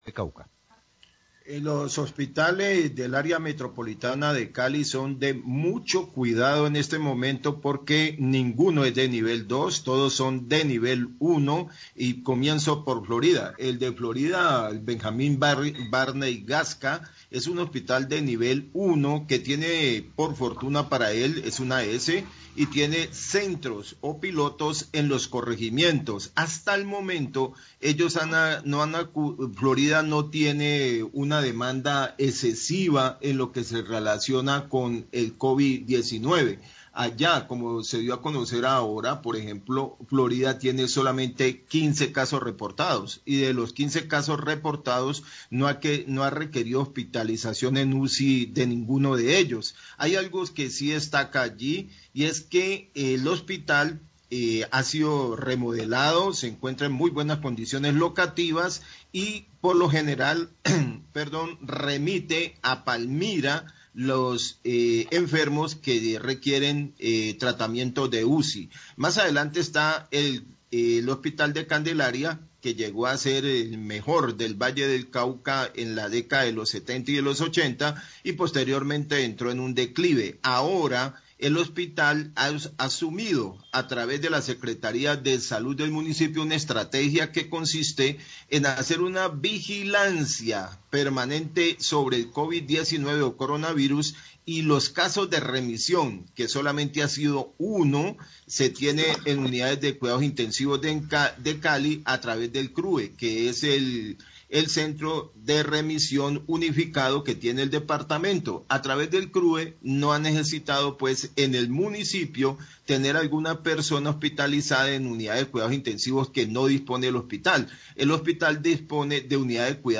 Radio
Informe sobre el manejo de los casos de covid-19 en los municipios cercanos a Cali.